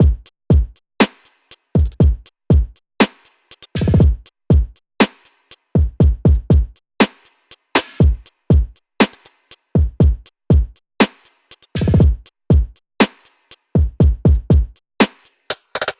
AV_Dirty_Drums_120bpm
AV_Dirty_Drums_120bpm.wav